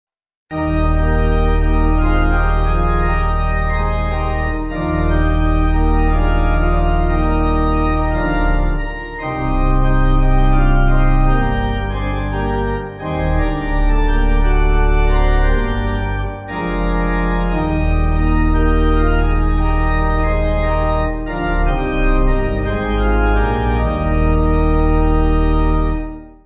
Organ
(CM)   5/Eb